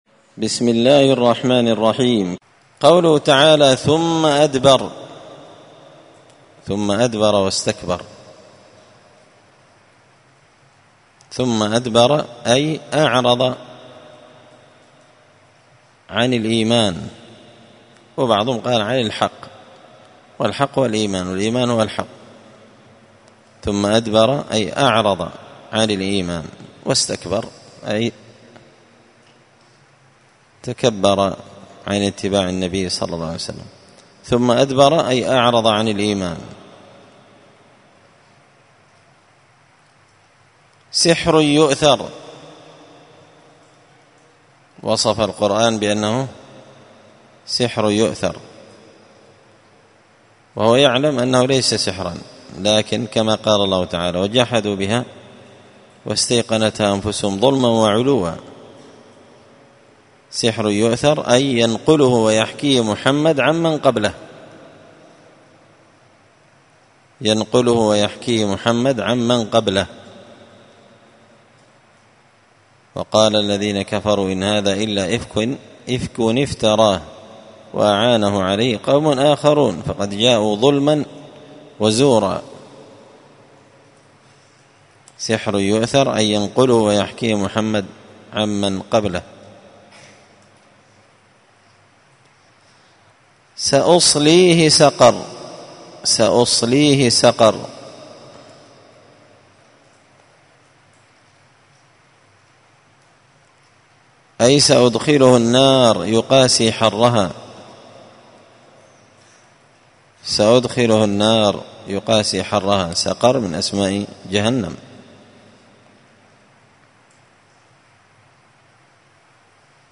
زبدة الأقوال في غريب كلام المتعال الدرس السابع والتسعون (97)